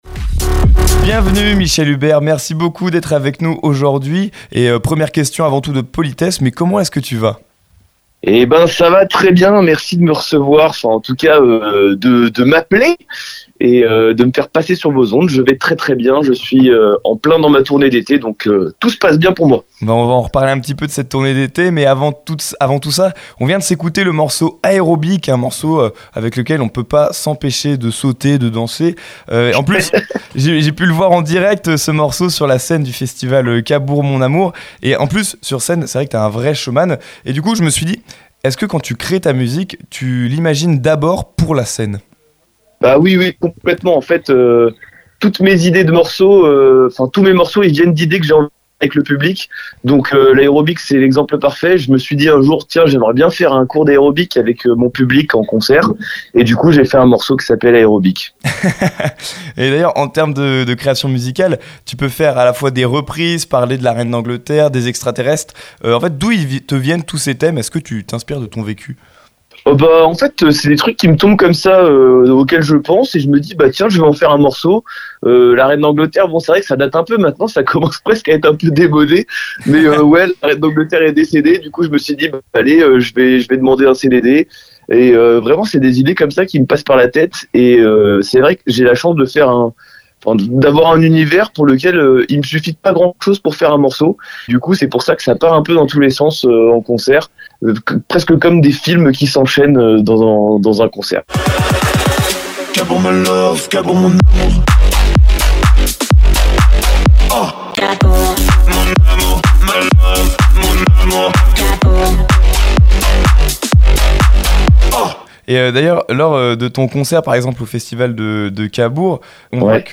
Mystery Machine Jullouville